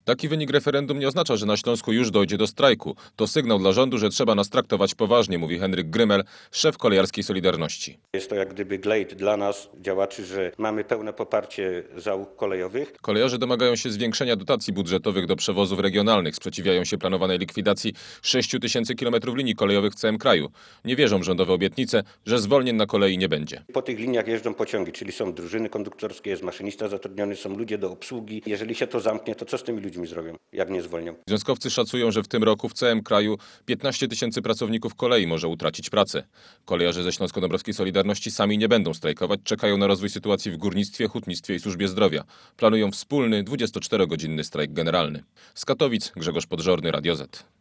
Słuchaj naszego reportera